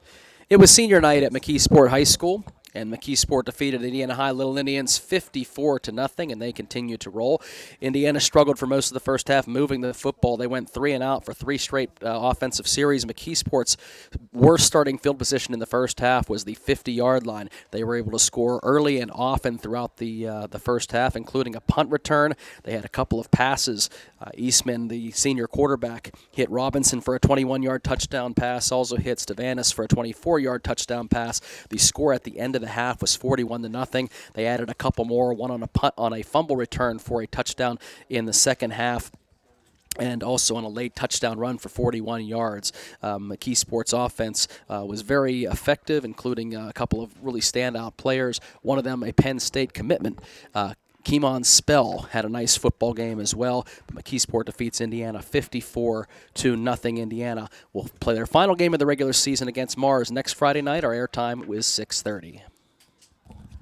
hsfb-indiana-vs-mckeesport-recap.wav